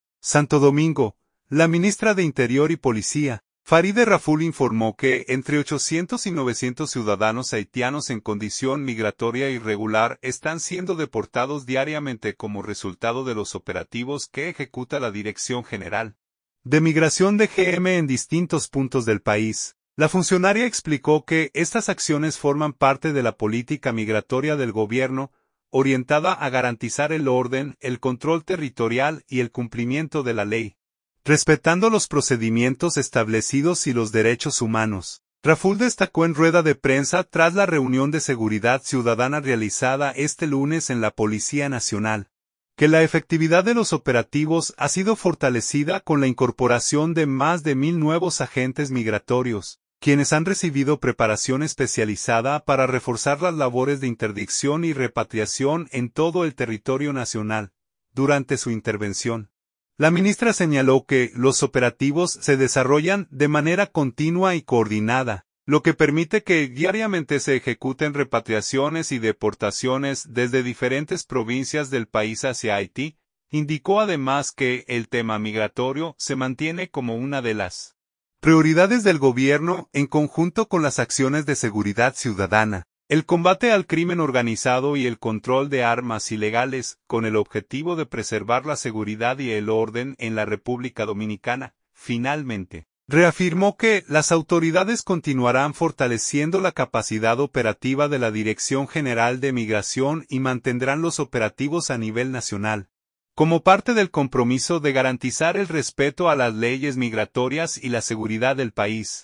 Raful destacó en rueda de prensa tras la reunión de seguridad ciudadana realizada este lunes en la Policía Nacional, que la efectividad de los operativos ha sido fortalecida con la incorporación de más de mil nuevos agentes migratorios, quienes han recibido preparación especializada para reforzar las labores de interdicción y repatriación en todo el territorio nacional.